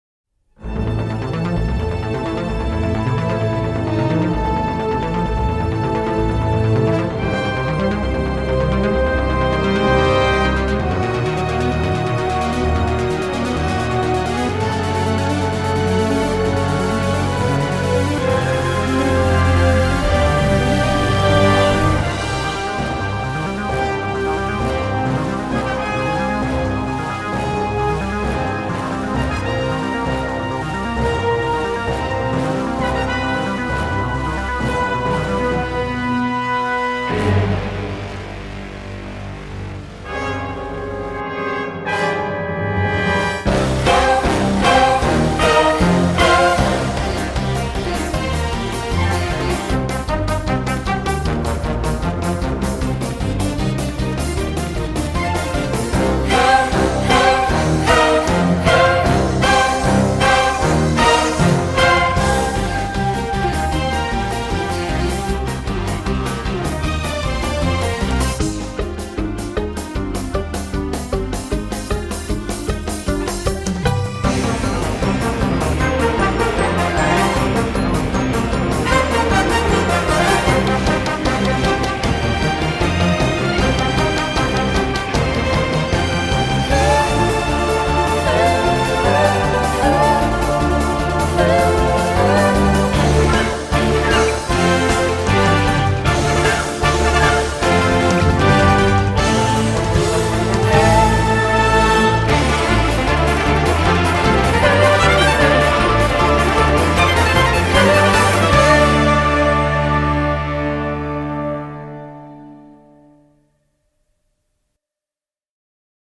Un petit score hybride, vraiment pas dégueulasse.